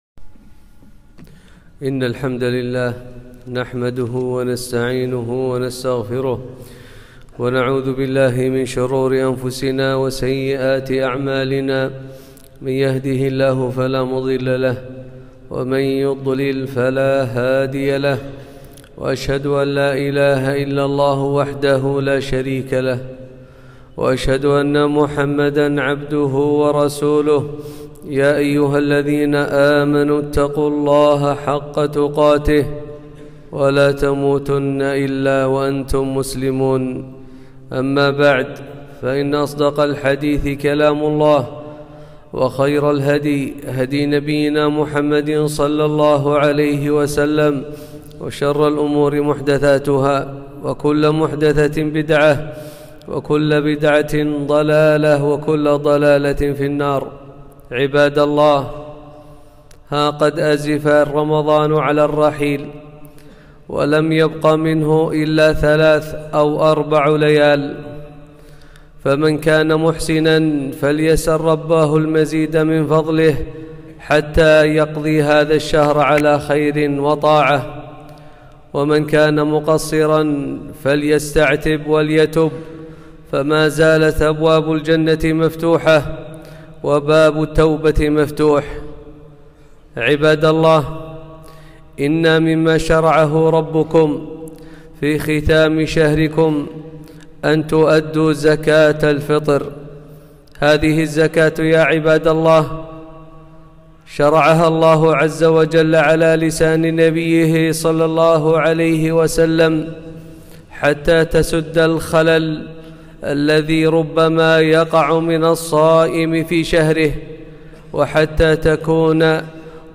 خطبة - زكاة الفطر